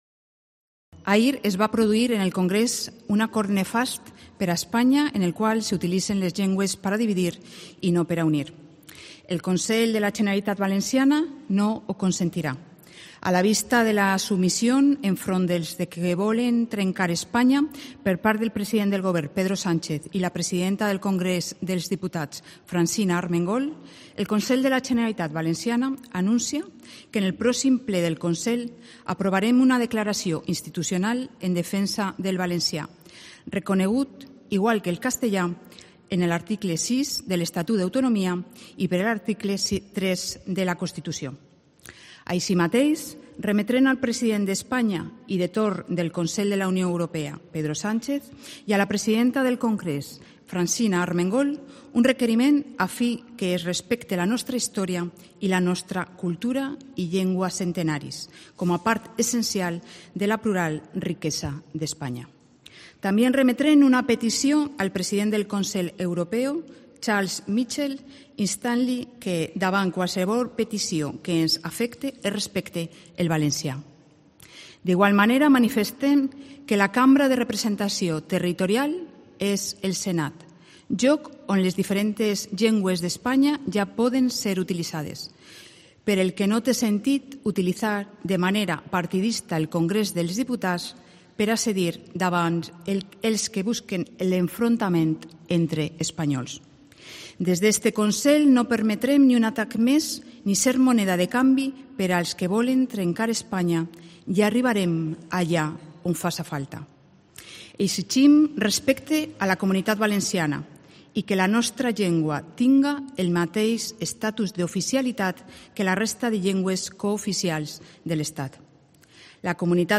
Declaración institucional de Susana Camarero